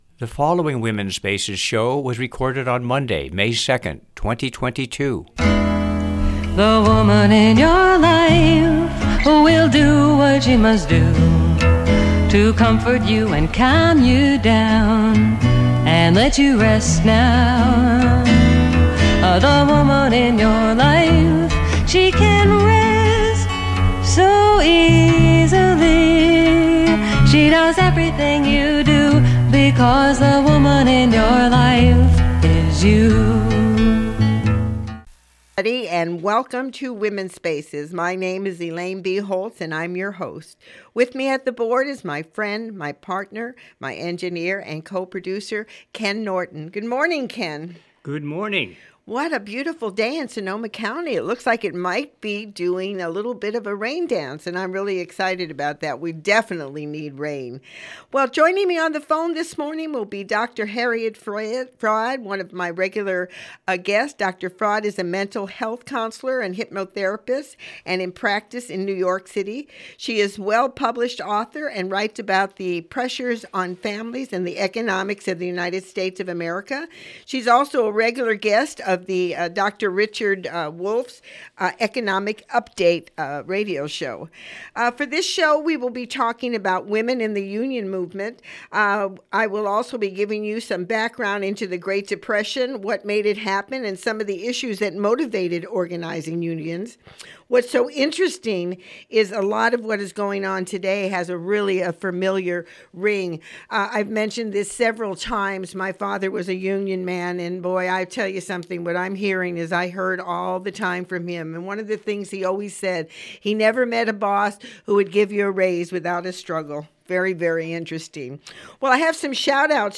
Original Radio Show ID: WSA220502 Listen to the Show on the Mp3 Player below Downloadtheshow